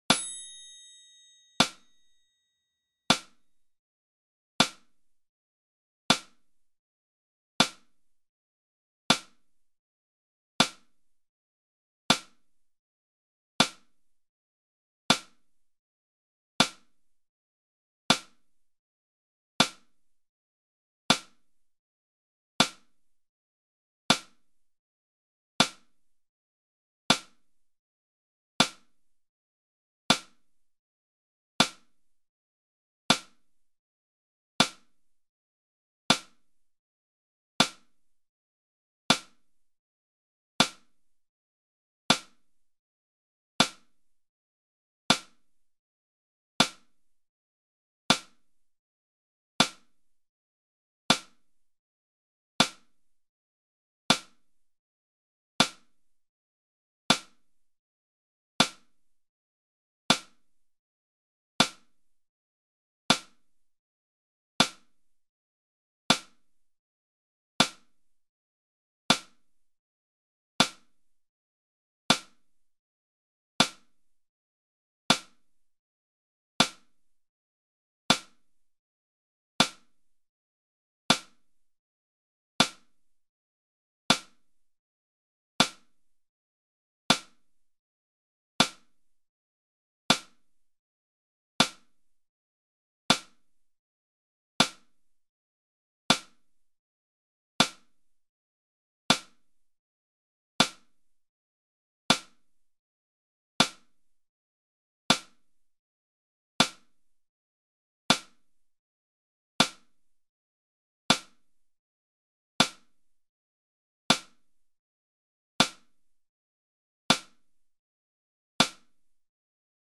40_BPM_Metronome.mp3